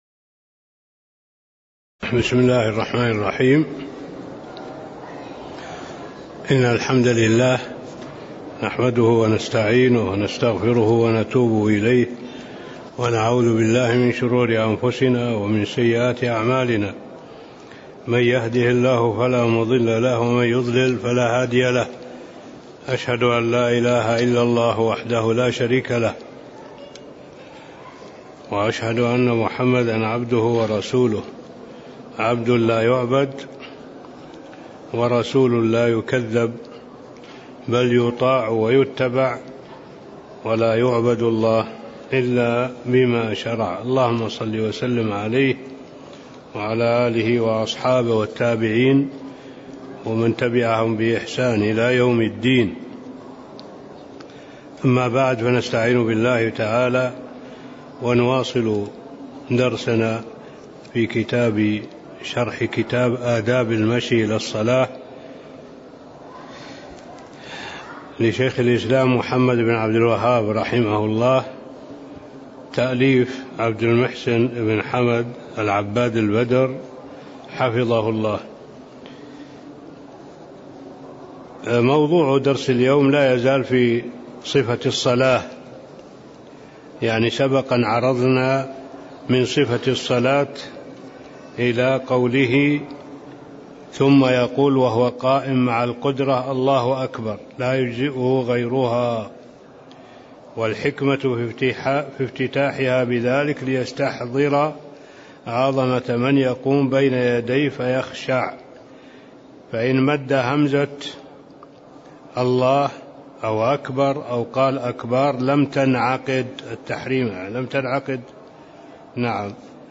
تاريخ النشر ٧ صفر ١٤٣٦ هـ المكان: المسجد النبوي الشيخ: معالي الشيخ الدكتور صالح بن عبد الله العبود معالي الشيخ الدكتور صالح بن عبد الله العبود باب صفة الصلاة (08)